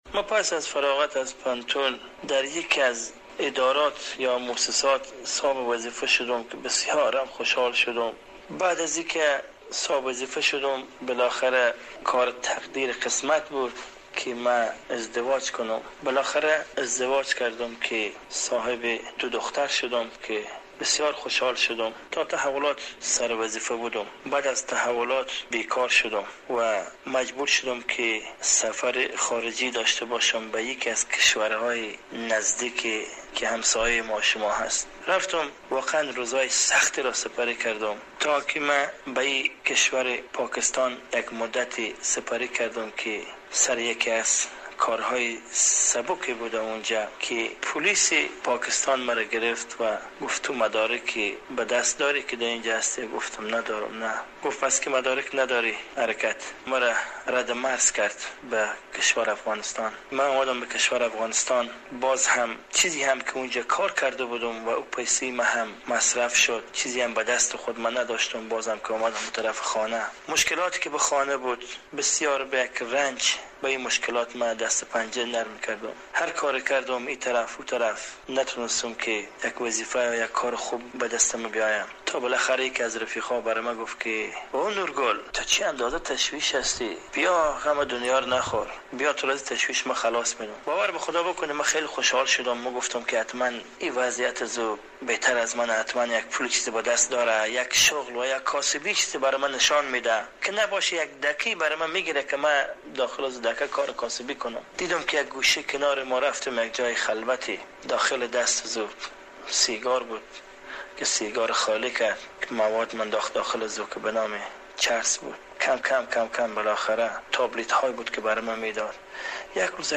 تصویر آرشیف در این مصاحبه جبنه تزئینی دارد